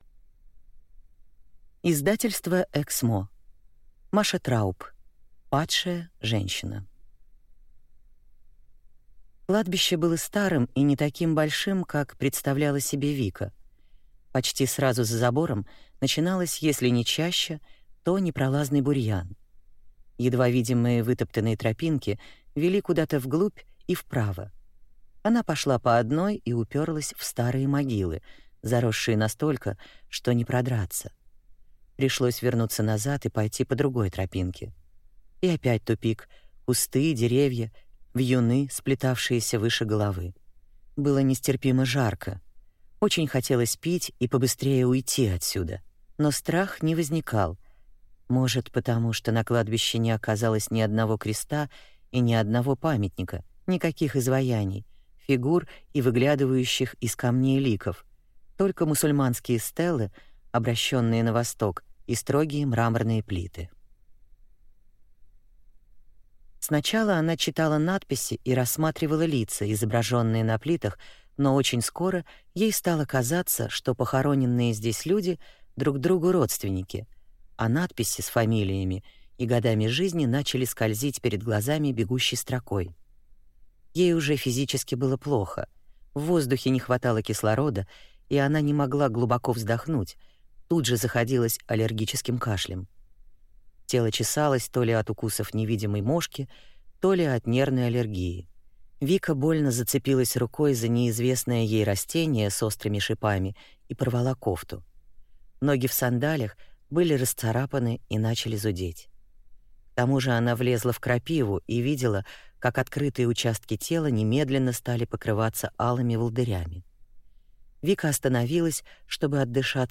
Аудиокнига Падшая женщина | Библиотека аудиокниг